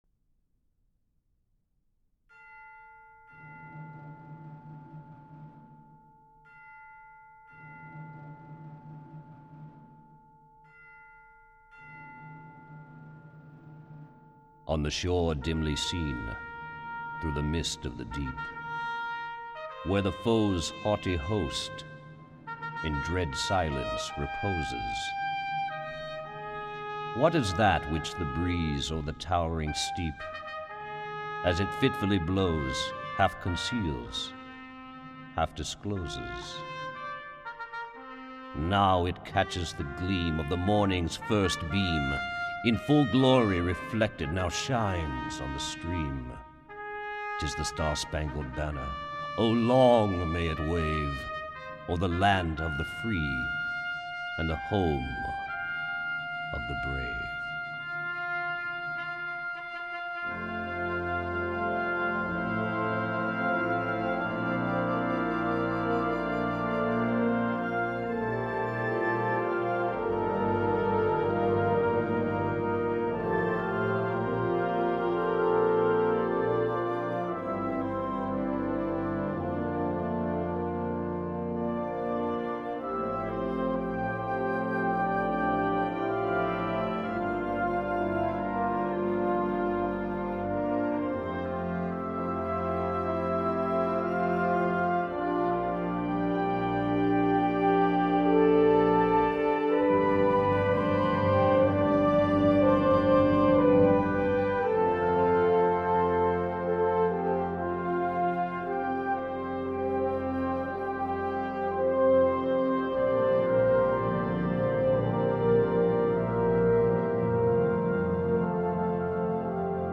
編成：吹奏楽